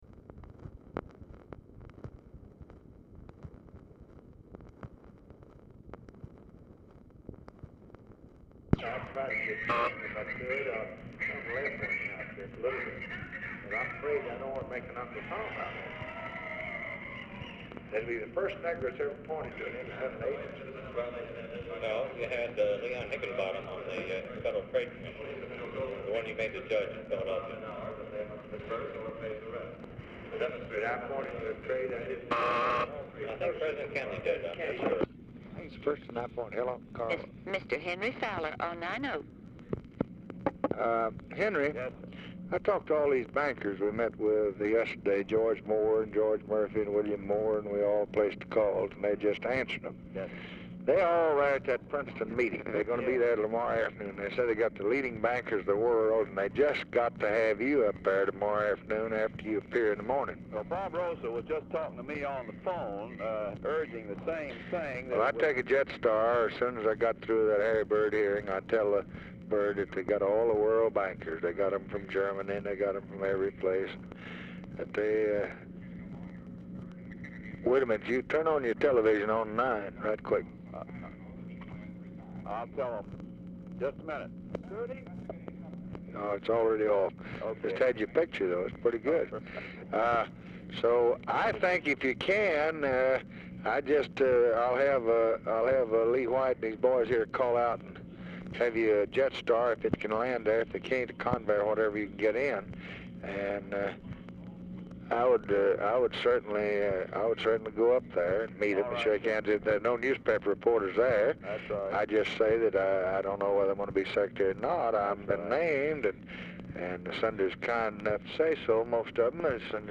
OFFICE CONVERSATION PRECEDES CALL; TV IS PLAYING IN BACKGROUND
Format Dictation belt
Location Of Speaker 1 Oval Office or unknown location
Specific Item Type Telephone conversation